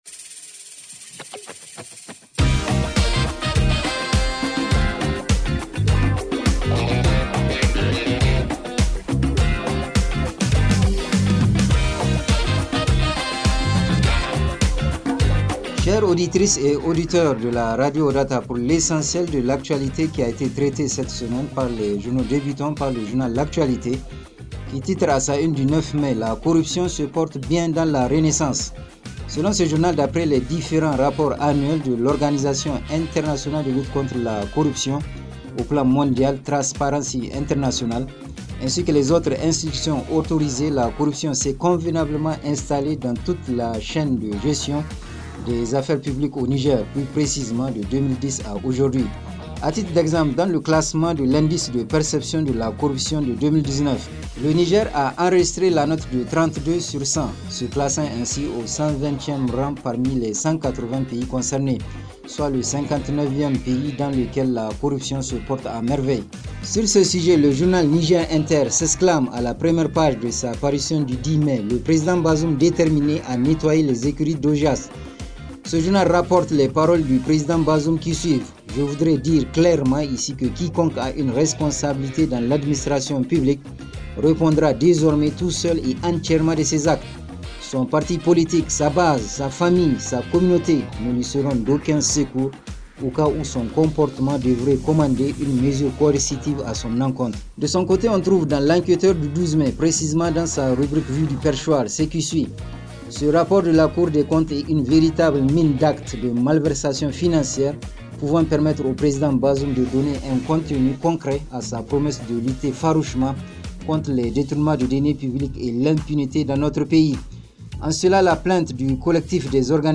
Revue de presse en français
revue-de-presse-12.mp3